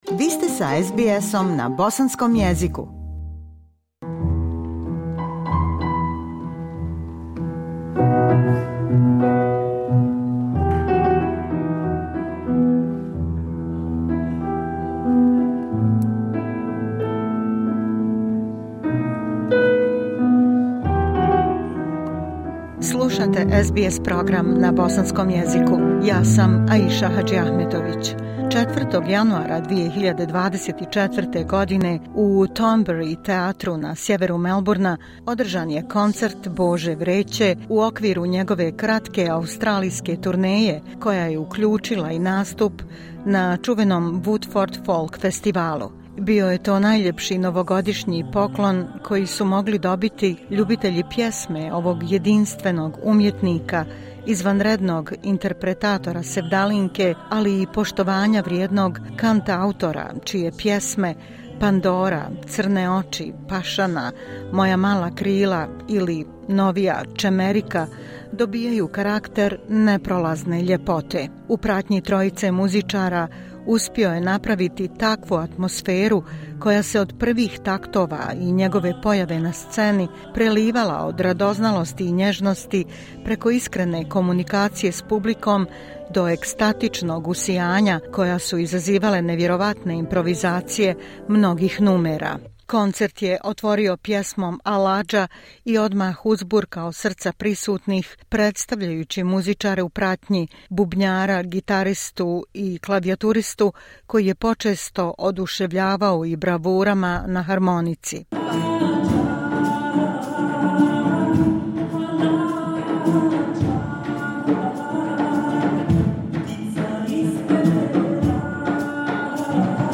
4. januara u Thornbery teatru na sjeveru Melbournea održan je koncert bosanskohercegovačke zvijezde sevdaha Bože Vreće u okviru njegove kratke australijske turneje, koja je uključila i nastup na čuvenom Woodford Folk Festivalu.
U pratnji trojice muzičara priredio je nezaboravan umjetnički doživljaj, preplavljen dobrim vibracijama, energijom pozitive i emocijama ljubavi.
Napravili su sjajnu atmosferu, koja se od prvih taktova i Božine pojave na sceni prelivala od radoznalosti i nježnosti, preko iskrene komunikacije s publikom do ekstatičnog usijanja koje su izazivale njihove instrumentalne improvizacije mnogih numera.